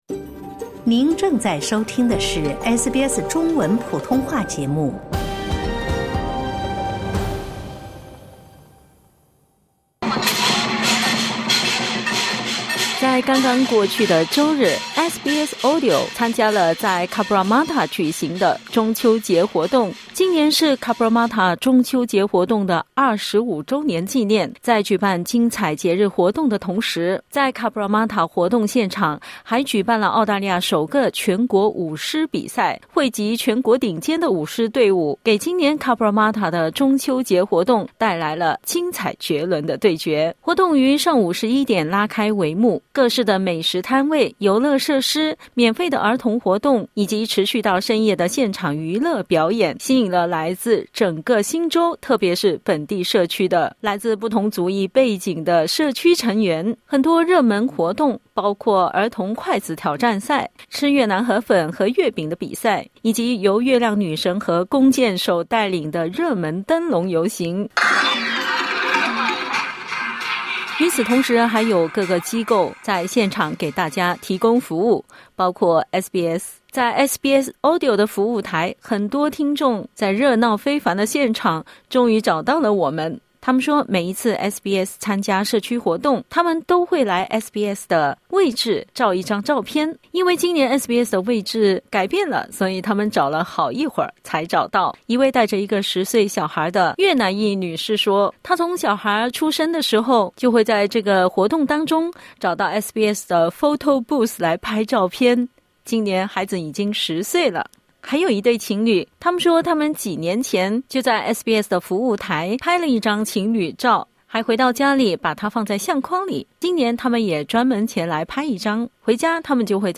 周日的社区中秋庆祝活动中，大量听众朋友来到SBS设置的服务台，下载SBS AUDIO APP，以便在更多平台随时随地收听SBS的音频节目。 （点击音频收听详细报道）